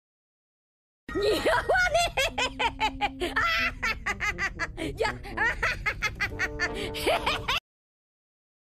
One Piece Luffy Laugh Sound Effect